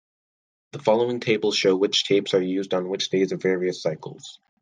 Pronounced as (IPA) /ˈsaɪkəlz/